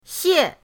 xie4.mp3